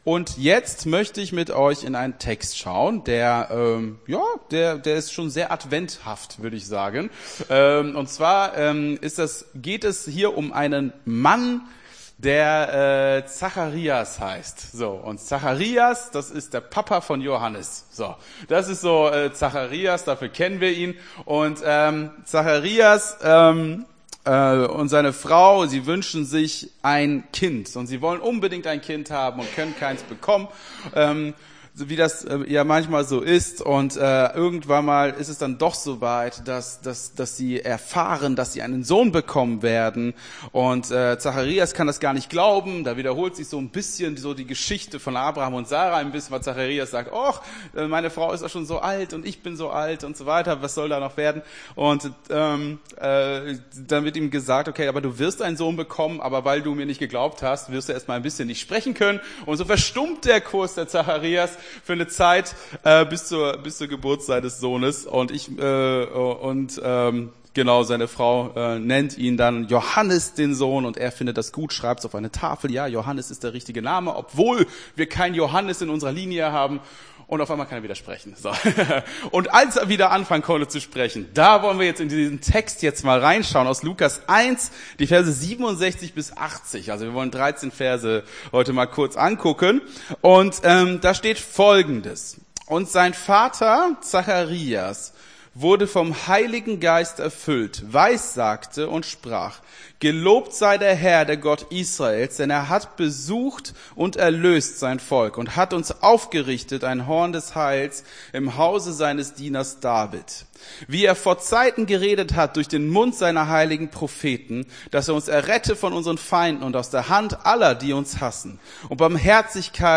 Gottesdienst 17.12.23 - FCG Hagen